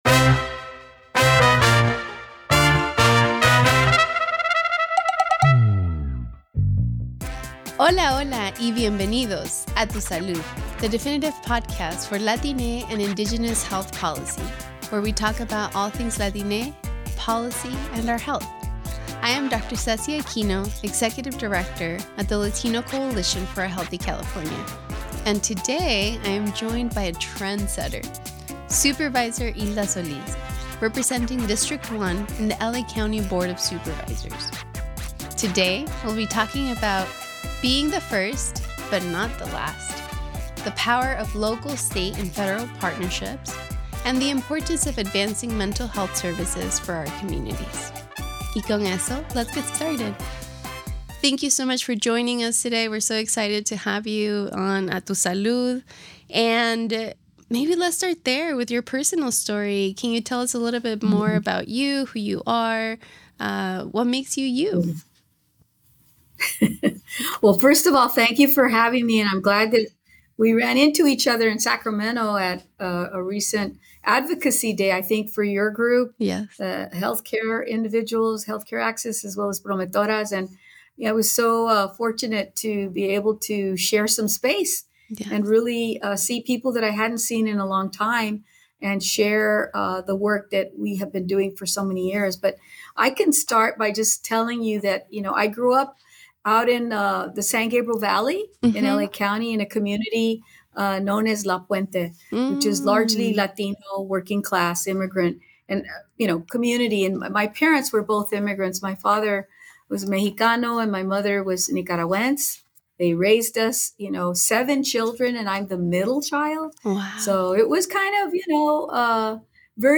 Join this dynamic discussion between two ceiling-breaking Latina women as we discuss the importance of Latina leadership, community promotores, the role of language equity in public health, and the incredible potential of Latine civic engagement.